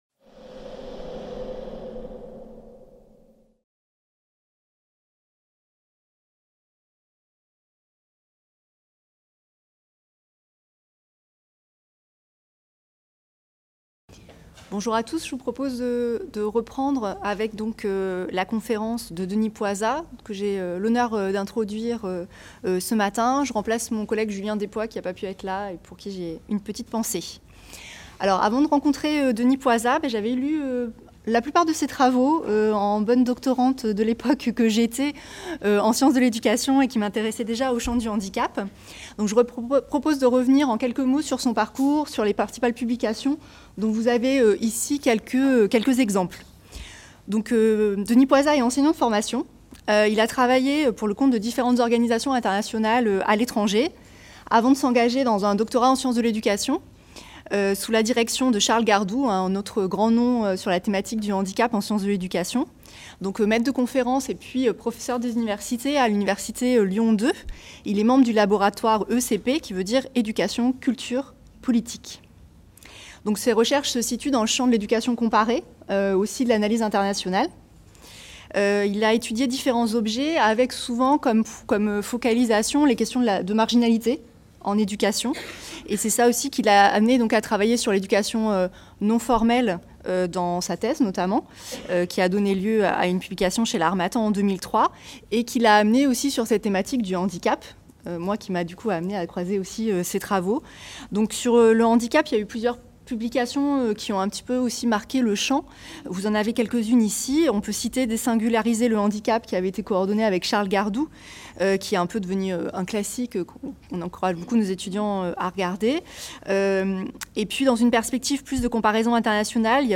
Conférence 3